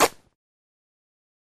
Tear | Sneak On The Lot